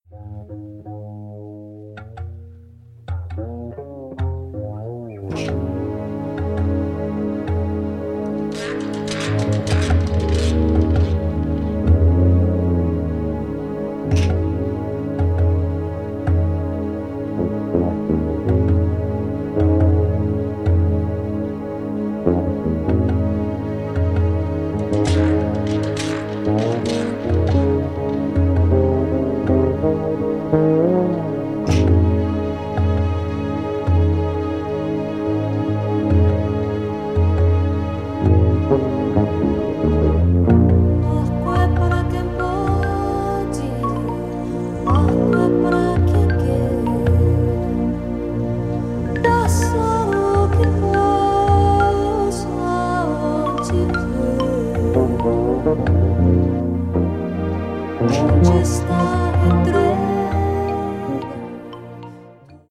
Recorded in 1983, the album is a musical gem.
Remastered.